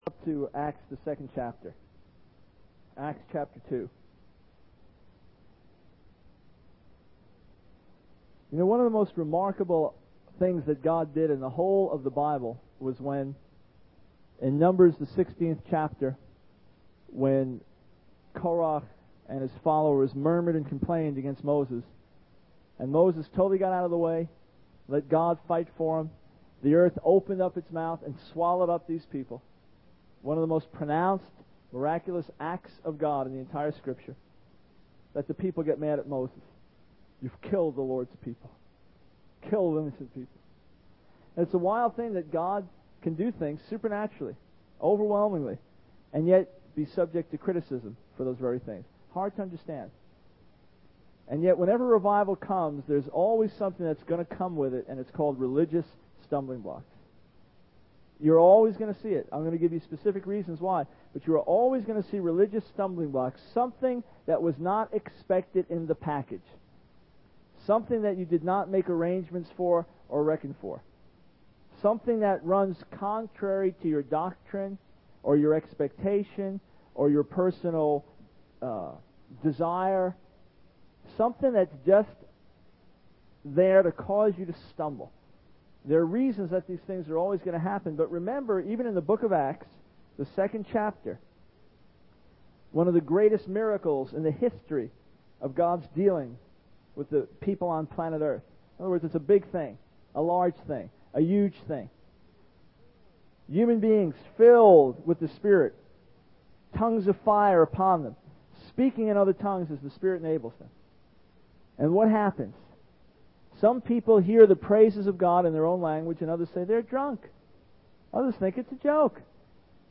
The sermon emphasizes the need to understand and overcome religious stumbling blocks.